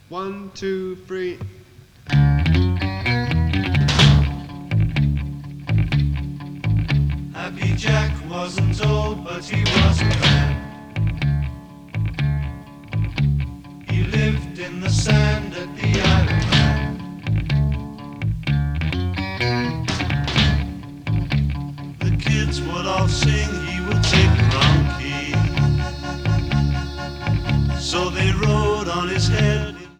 Stereo Mix